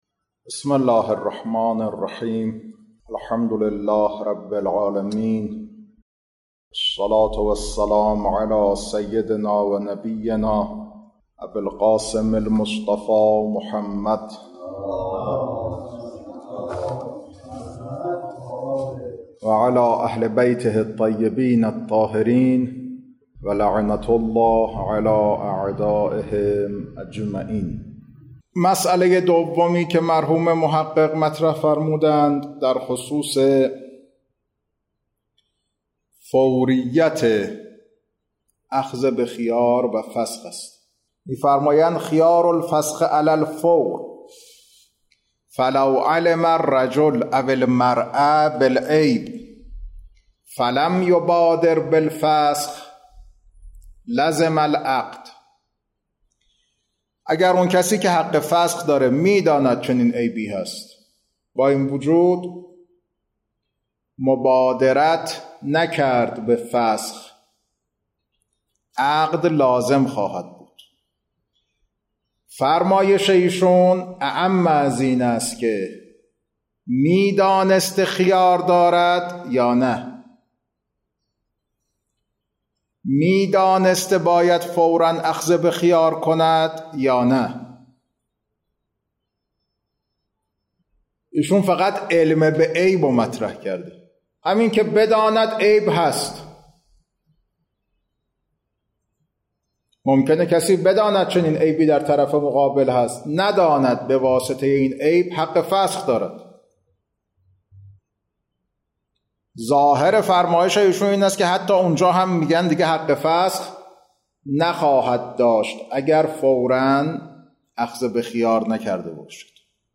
خارج فقه